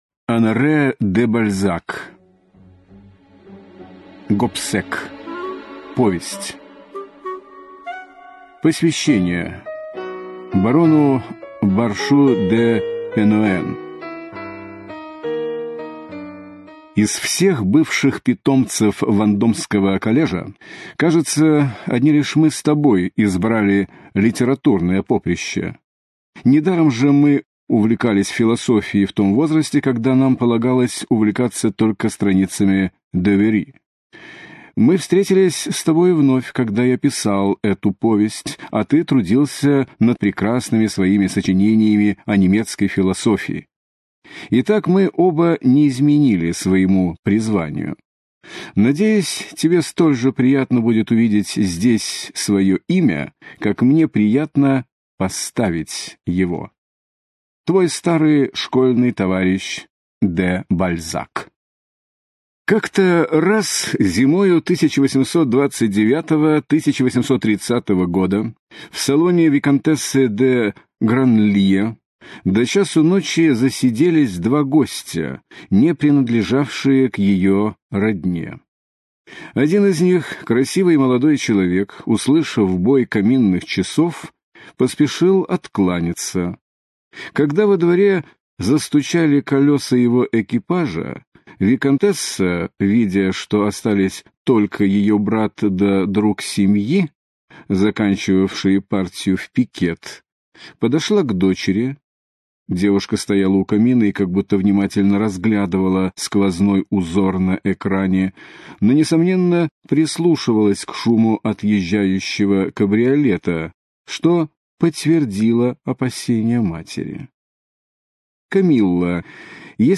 Аудиокнига Гобсек - купить, скачать и слушать онлайн | КнигоПоиск